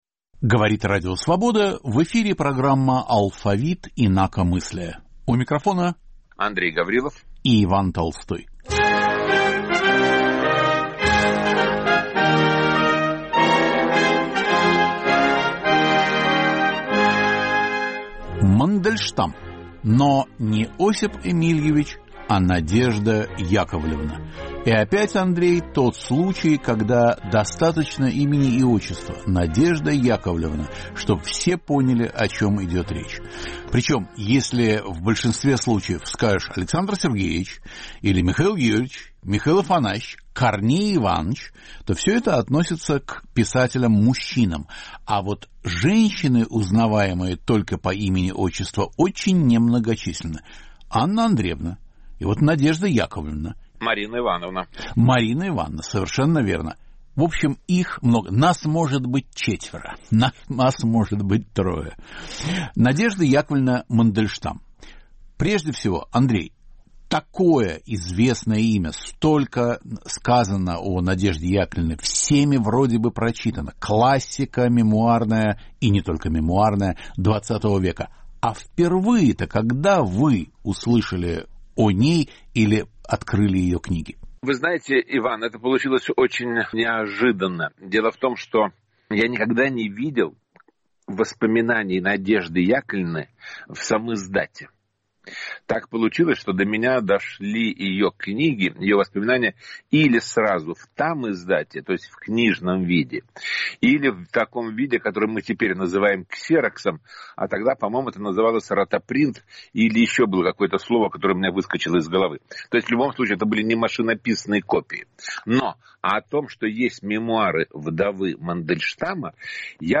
В программе звучат фрагменты архивных записей Радио Свобода, которое передавало воспоминания вдовы Мандельштама в 1970-е годы.